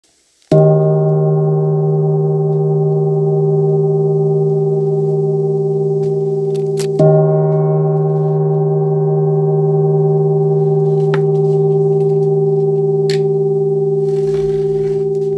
Gong, Tibetan Handmade, Wind Gong, Flat Gong
Material Bronze
Unlike traditional gongs with upturned rims, the wind gong lacks a pronounced cylindrical shape, resulting in unobstructed vibrations that create a deep and immersive sound with a wide range of tonal qualities.